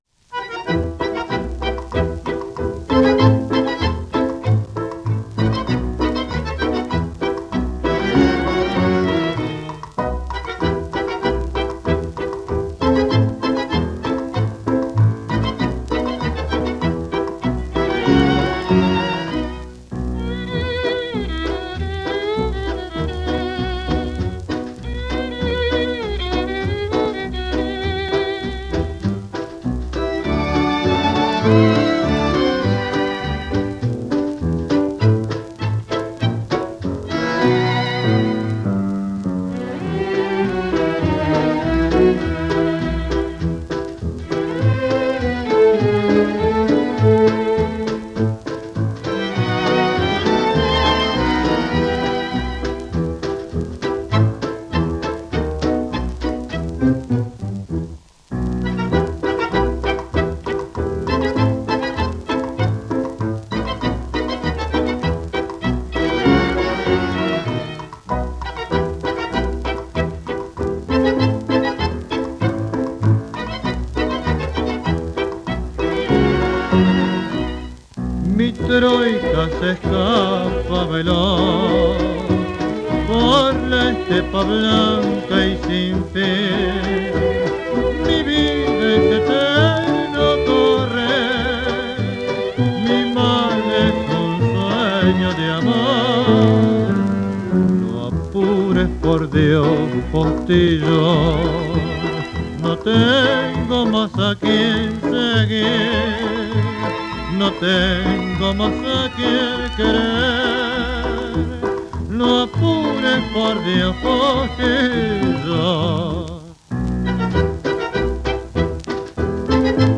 FOXTROT
Хороший фокстрот.Спасибо!